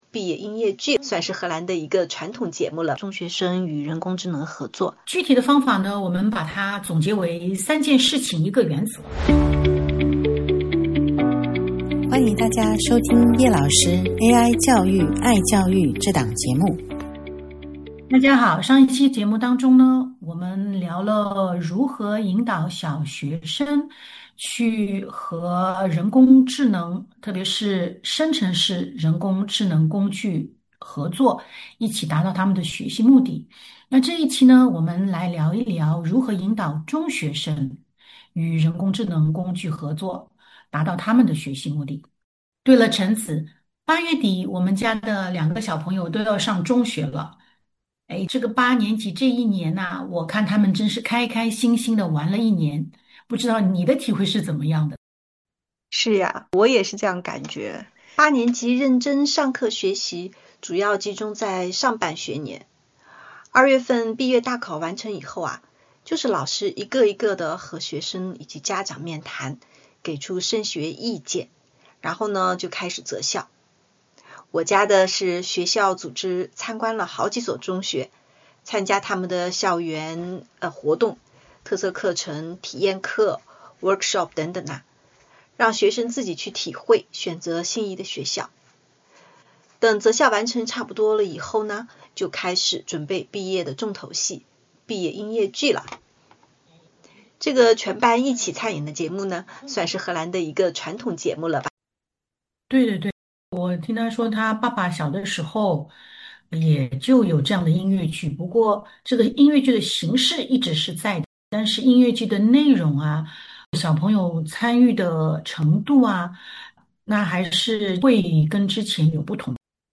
本期节目，两位主持人讨论的主题是如何引导中学生与AI协作，共同学习。